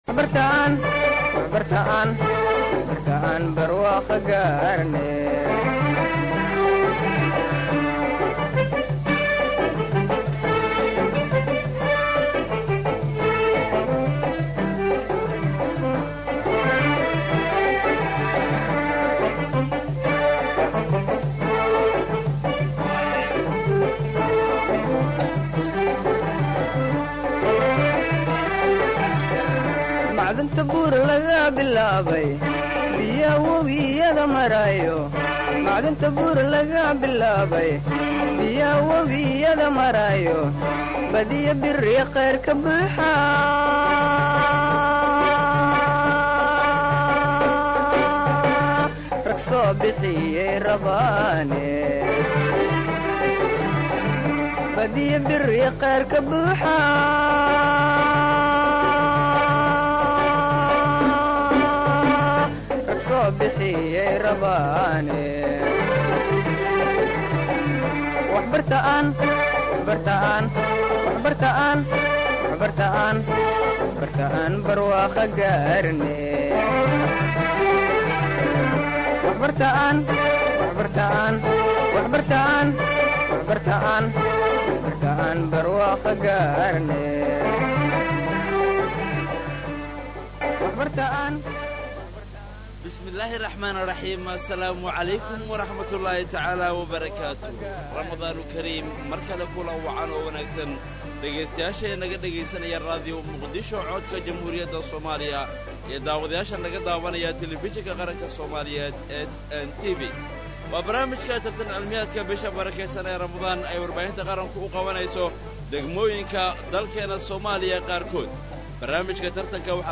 Tartan Cilmiyeedka bisha Ramadaan oo ay si wada jir ah u baahinayaan Radio Muqdisho iyo Telefishinka Qaranka Soomaaliyeed ee SNTV,